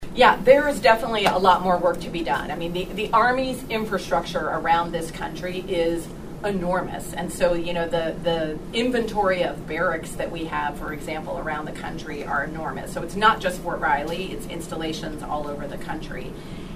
Army Secretary Christine E Wormuth visited one of the newly remodeled barracks and spoke about the state of the Army’s overall infrastructure.